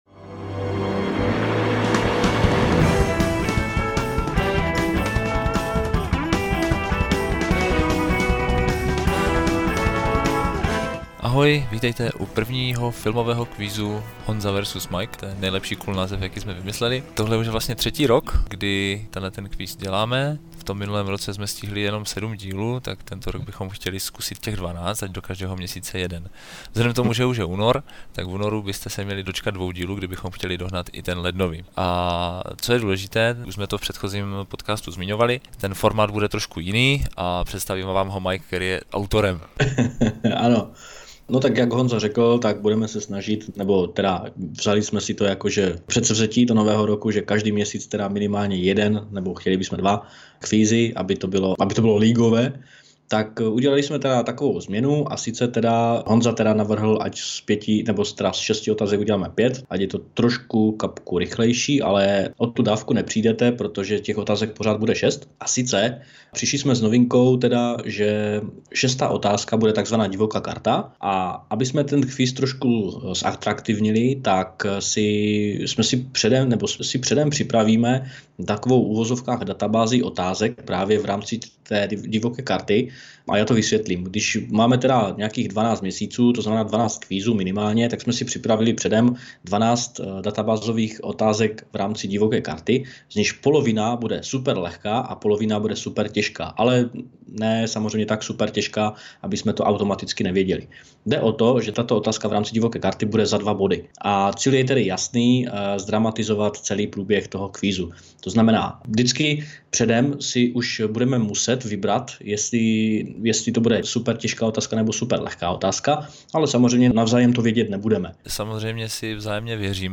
Kvíz taky dostal nový audio kabátek. Největší novinkou je takzvaná divoká karta, tedy možnost vybrat si otázku, která je za dva body.